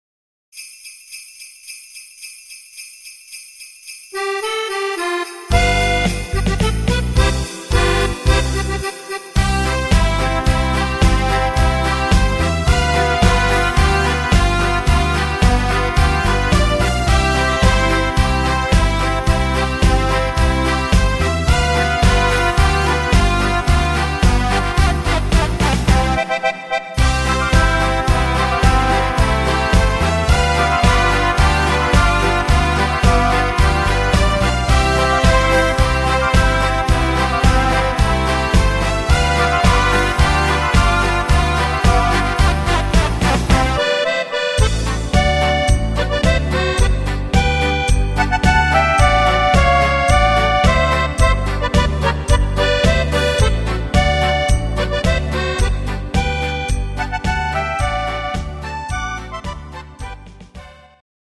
instr.